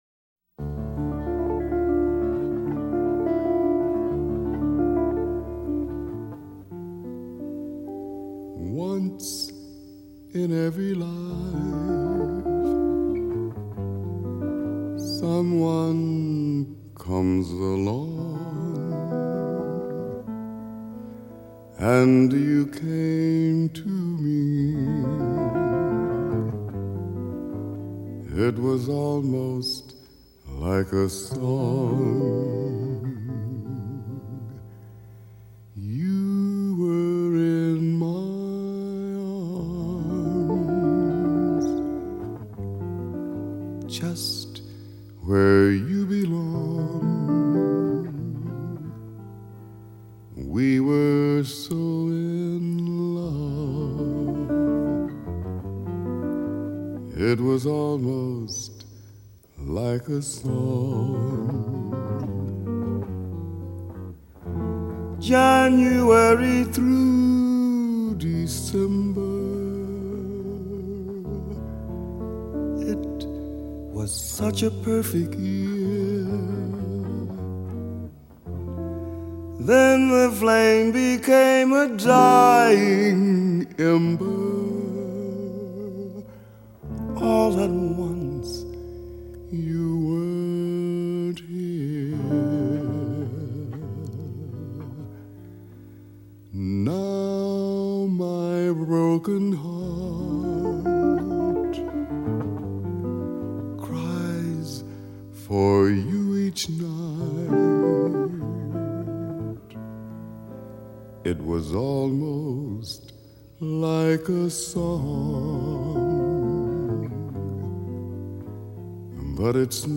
jazz soundtrack